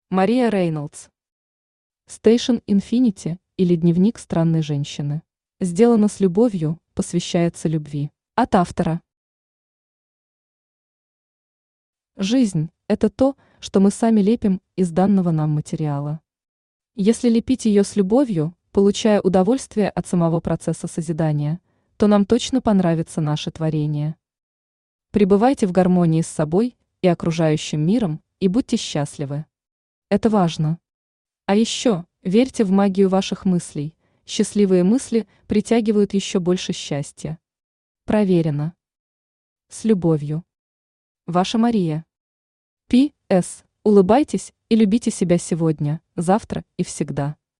Аудиокнига Station Infinity, или Дневник странной женщины | Библиотека аудиокниг
Aудиокнига Station Infinity, или Дневник странной женщины Автор Мария Рейнолдс Читает аудиокнигу Авточтец ЛитРес.